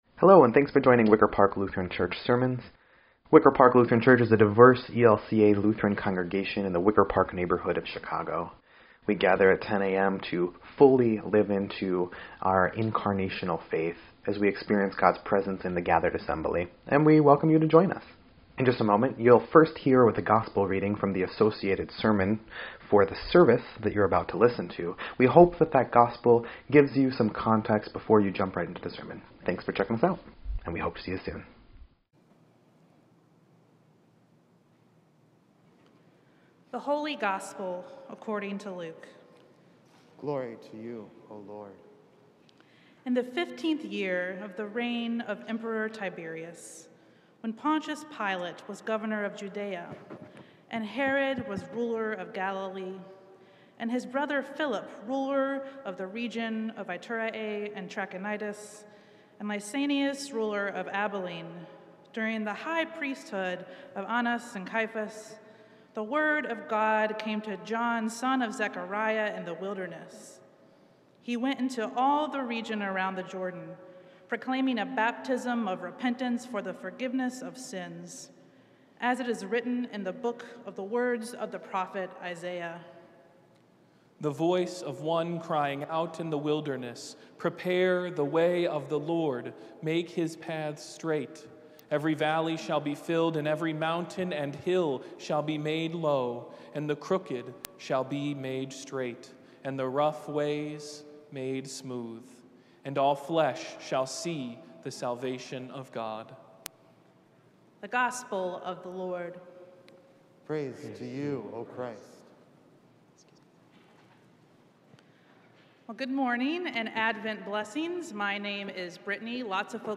12.5.21-Sermon_EDIT.mp3